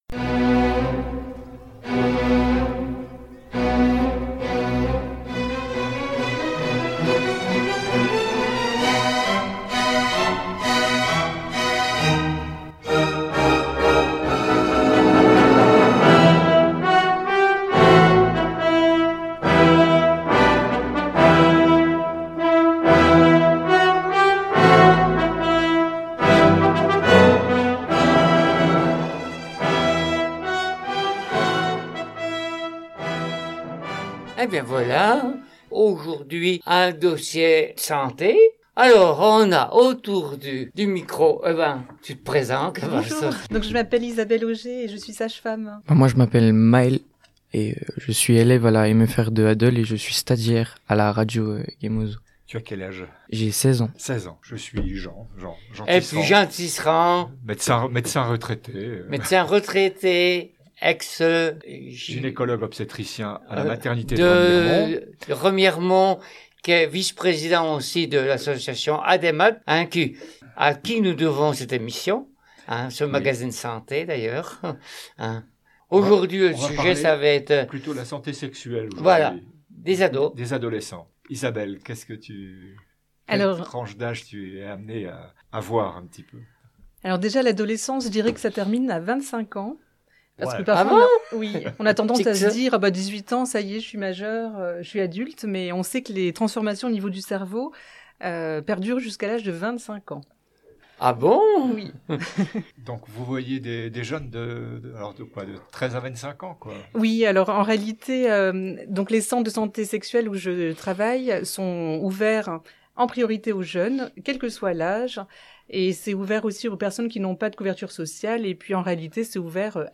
???? Une discussion croisée entre professionnels et jeunes, pour mieux comprendre les enjeux et ouvrir le dialogue sur un sujet qui nous concerne toutes et tous.